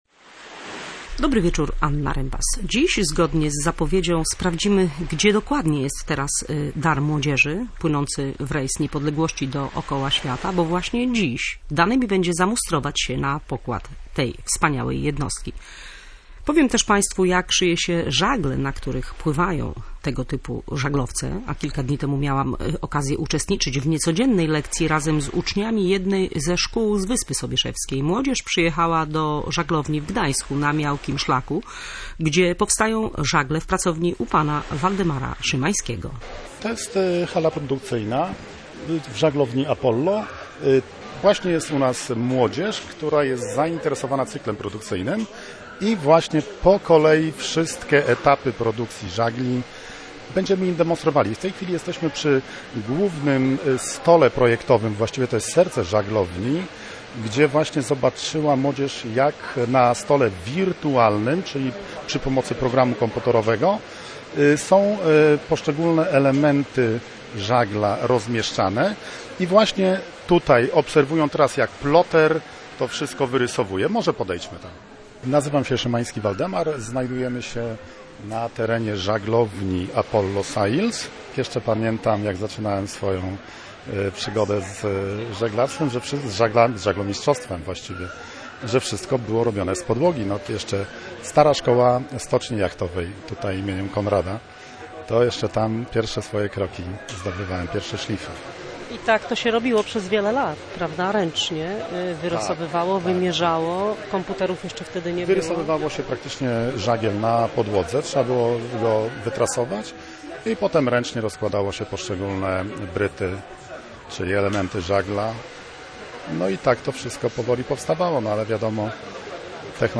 W audycji także o rekordzie przeładunkowym w DCT i o odbudowie polskiego przemysłu stoczniowego, a także o morskim potencjale i kondycji polskiego przemysłu stoczniowego, o czym mówił w Gdyni premier RP Mateusz Morawiecki.